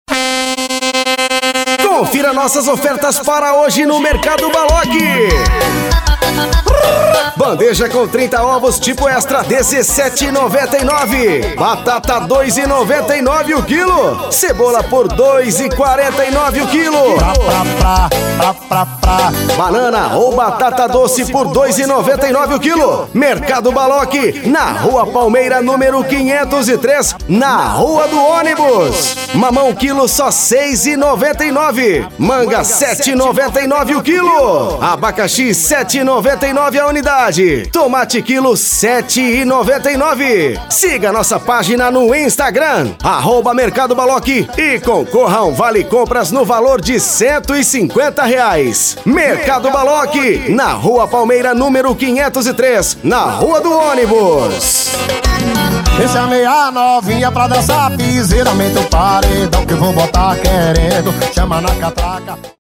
VAREJO CARRO DE SOM: